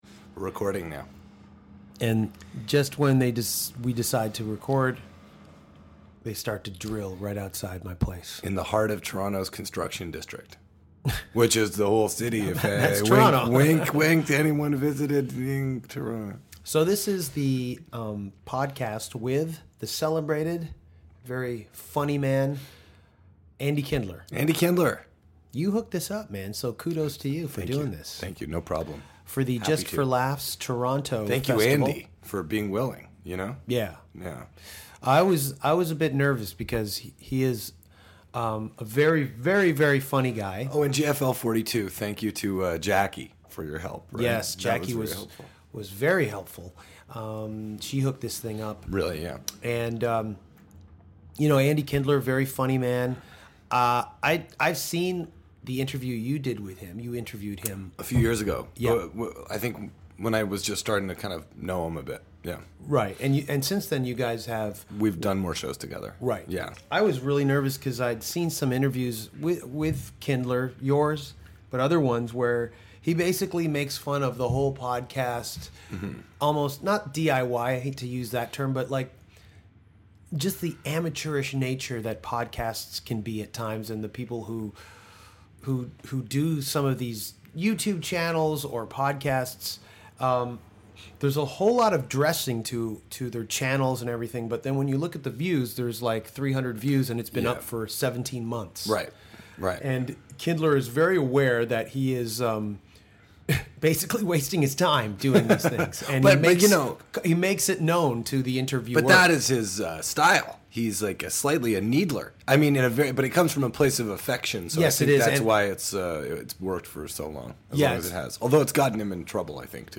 during the Just For Laughs Festival in Toronto